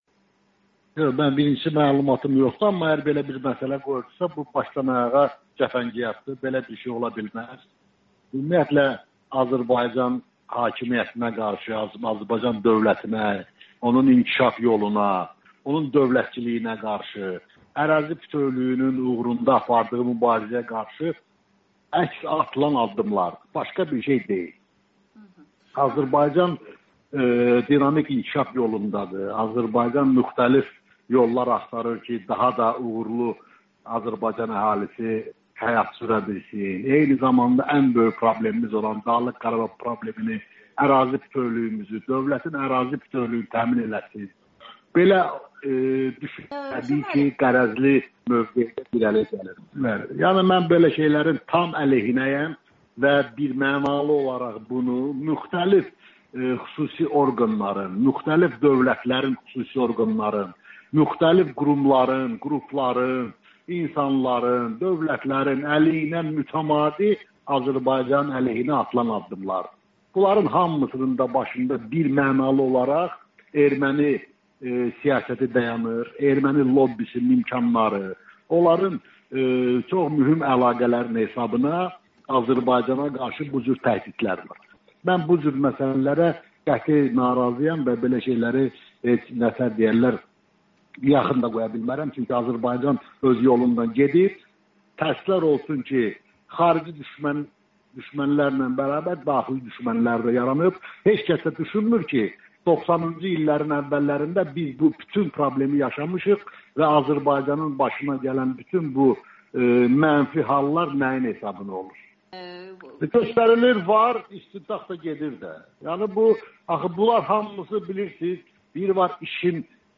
AzadlıqRadiosu bununla bağlı Azərbaycanın AŞPA-dakı nümayəndə heyətinin üzvü, deputat Rövşən Rzayevdən müsahibə götürüb.